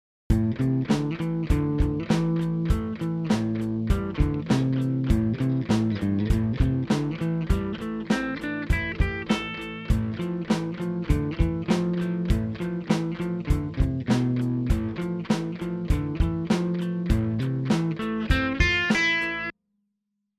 Both the natural minor scale and minor pentatonic scale have the same overall minor feel.
The following example has a simple lead guitar part over eight bars. Four of those bars have the minor pentatonic scale and four have the natural minor pentatonic scale:
Solo mixing the natural minor and minor pentatonic scales